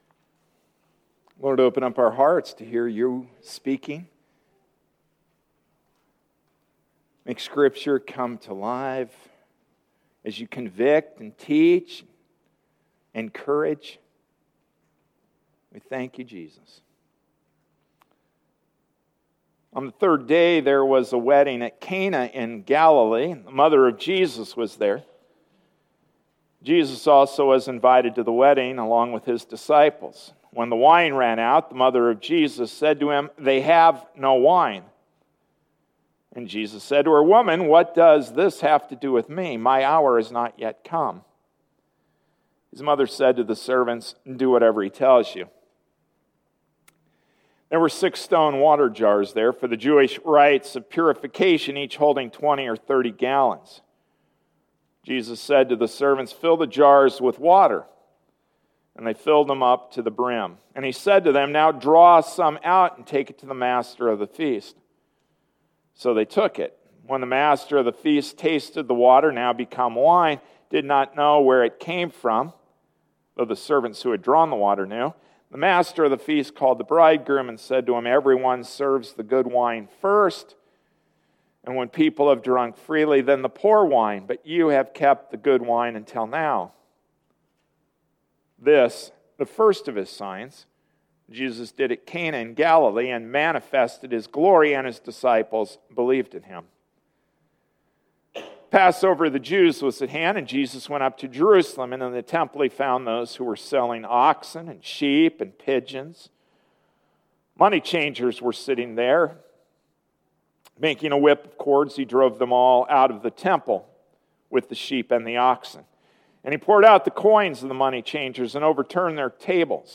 January 5, 2014 Something Old, Something New Passage: John 2:1-17 Service Type: Sunday Morning Service Ephesians 4:1-16 Introduction: (Therefore) in light of what Paul has written so far about our position in Christ.